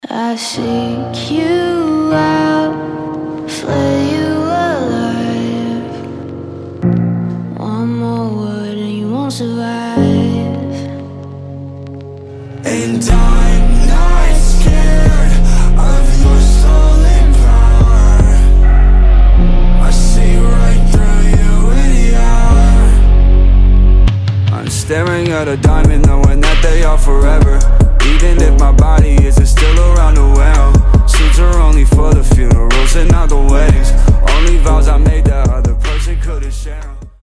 дуэт
alternative